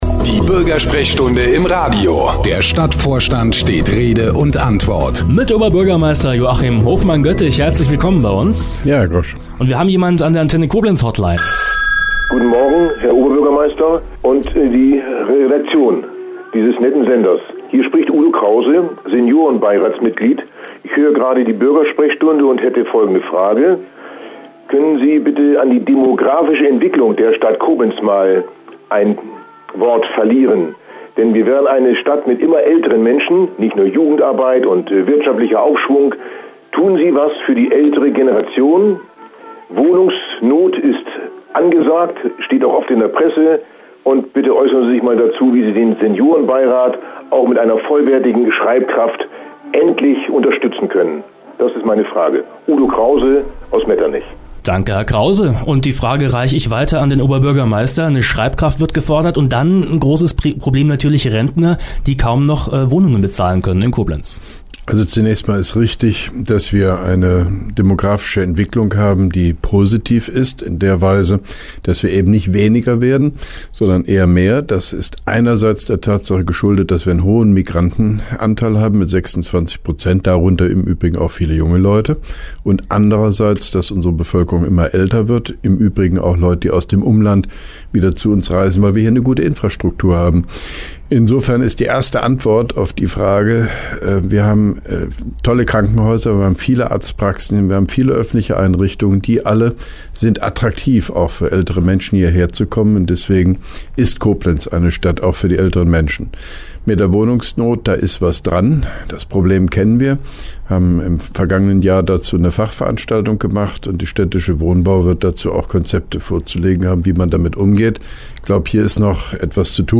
(3) Koblenzer Radio-Bürgersprechstunde mit OB Hofmann-Göttig 07.02.2012
Antenne Koblenz 98,0 am 07.02.2012, ca. 8.55 Uhr (Dauer 02:48 Minuten)